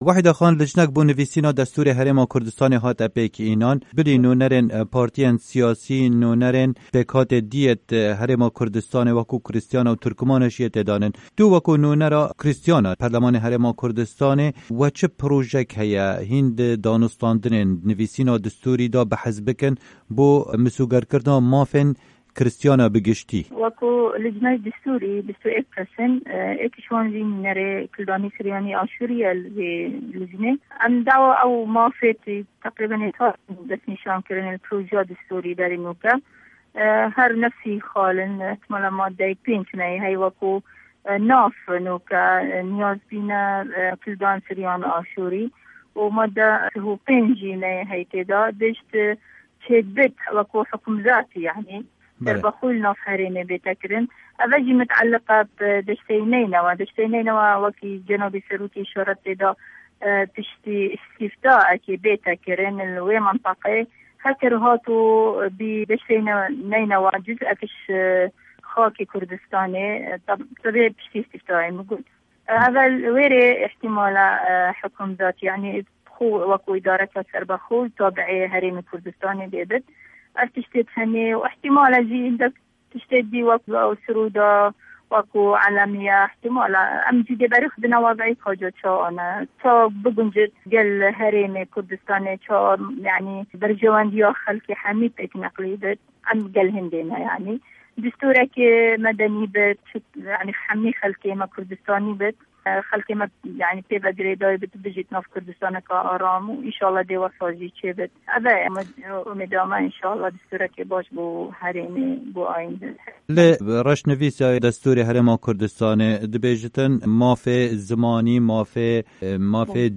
Endama Kirîstîyan a perlamanê Herêma Kurdistanê Wehida Yaqo di hevpeyvînekê da gote Dengê Amerîka, azadîya oil û eger çêbit durist kirna rebeverîyaka serbexw li deşta Mosîl daxewazên Kirîstîyan ye.
Hevpeyvin digel Wehîda Yaqo